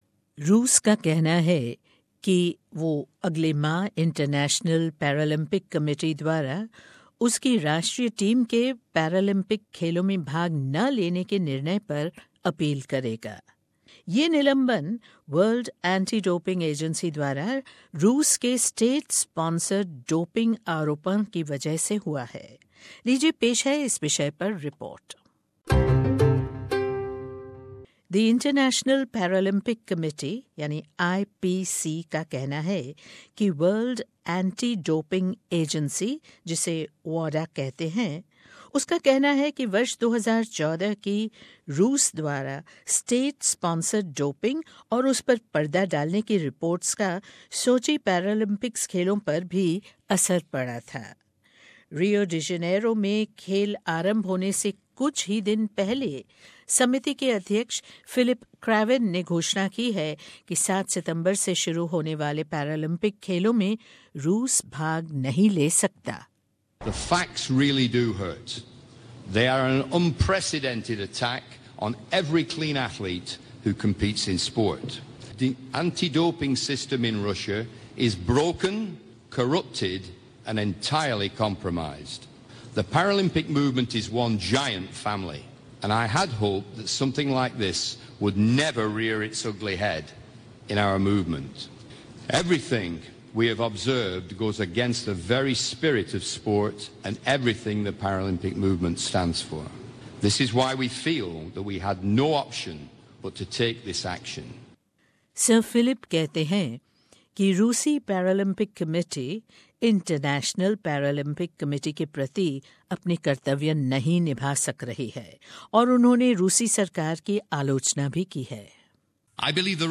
रूस का कहना है कि वो अंतरराष्ट्रीय परल्य्म्पिक समिति द्वारा उसकी राष्ट्रीय टीम की खेलों में भाग लेने की रोक पर अपील करेगा !ये निलंबन World Anti डोपिंग एजेंसी द्वारा डोपिंग के आरोपों की वजह से हो रहा है। सुनिये ये रिपोर्ट।